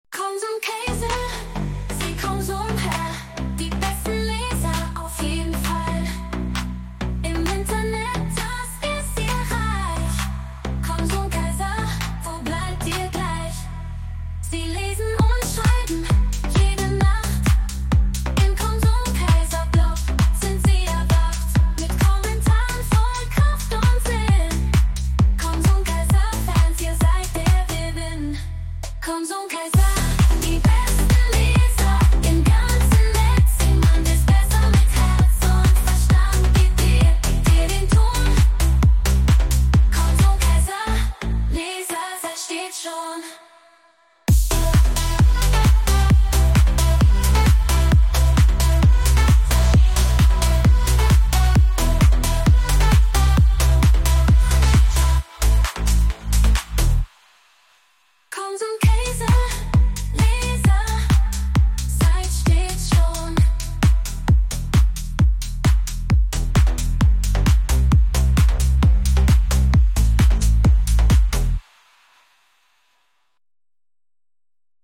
Also man füttert z.B. die KI mit Schlagworten wie „Eine hymnische Uptempo Nummer, die gefühlvoll beschreibt, wie toll der Konsumkaiser Blog ist, wie aussergewöhnlich die Leserinnen und Leser sind, und wie schön doch das Leben ist.“
In Sekunden wird dann ein neues Lied geschaffen, inklusive beinahe perfekt gesungenem Text, der auf all das eingeht, was man da nun gefüttert hatte.